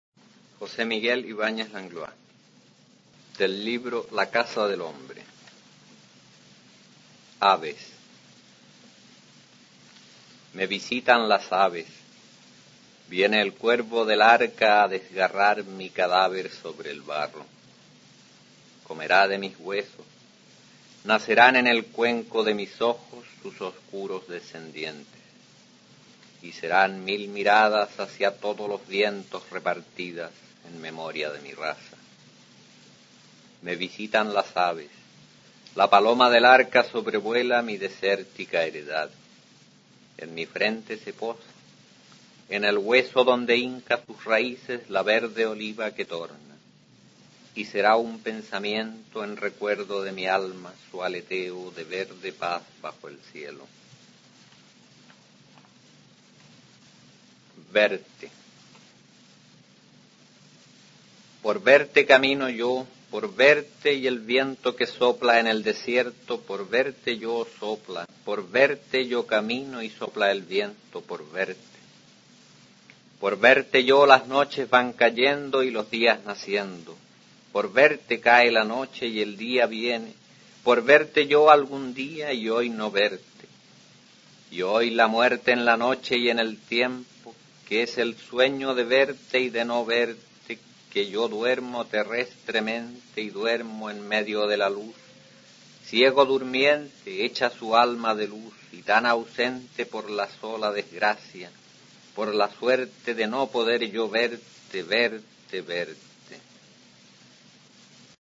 Aquí se puede escuchar al autor chileno José Miguel Ibáñez Langlois recitando sus poemas Avesy Verte, del libro "La casa del hombre" (1961).